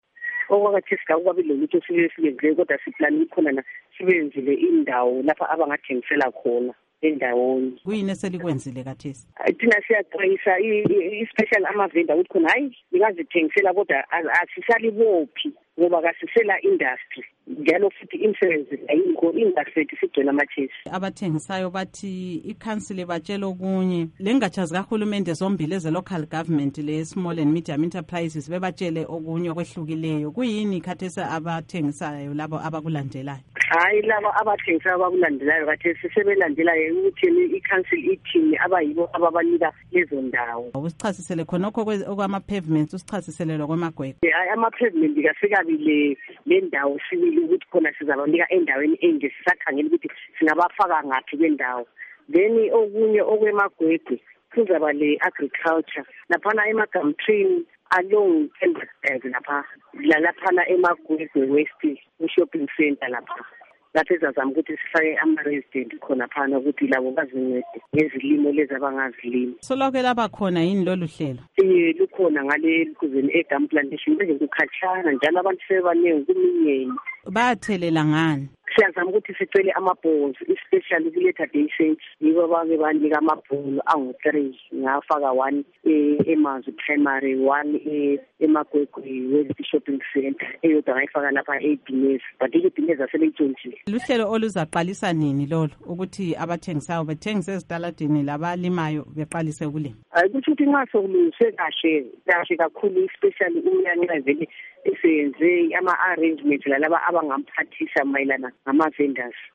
Ingxoxo LoKhansila Monica Lubimbi